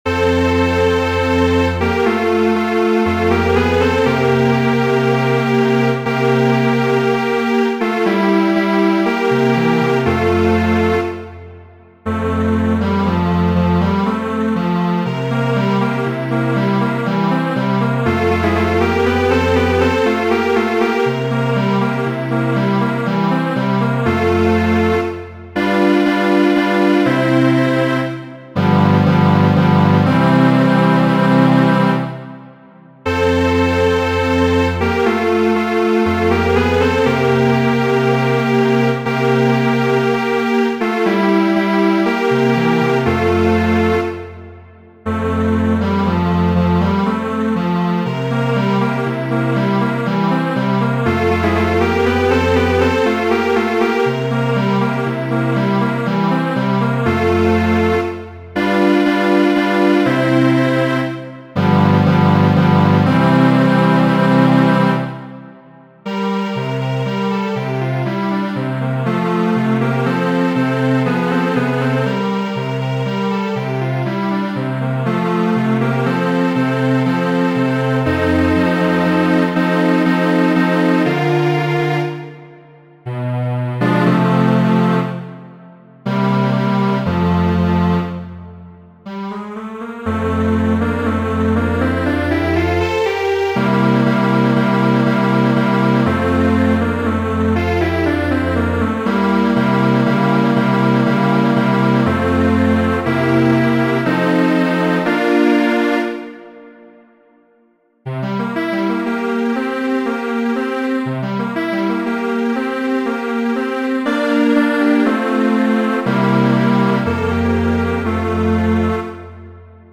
Cuarteto estas adapto mia pri la studo, verko 24-a 4-a de Fernando Sor.
cuarteto.mp3